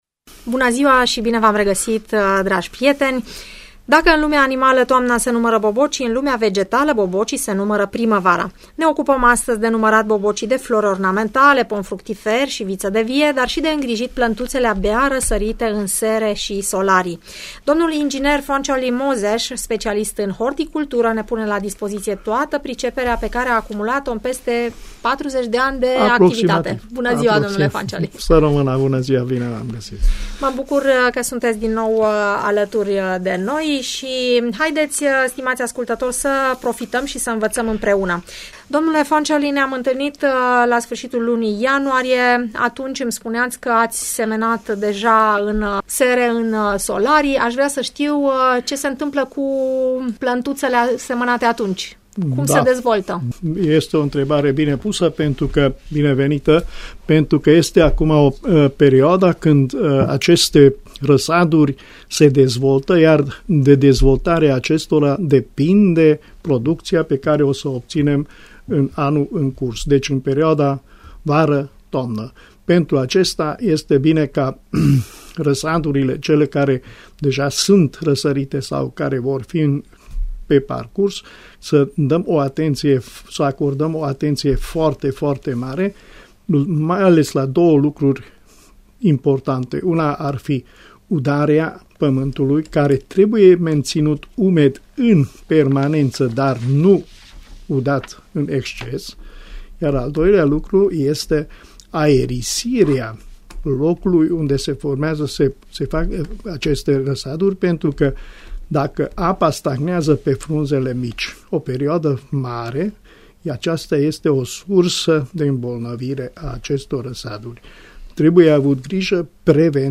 ne pune la dispoziție toată priceperea pe care a acumulat-o în peste 40 de ani de activitate în emisiunea Părerea ta, difuzată la Radio Tg Mureș. Vorbim despre îngrijirea micilor răsaduri, despre tratamentele pe care trebuie să le efectuăm pentru a avea recolte sănătoase și bogate de legume și zarzavaturi.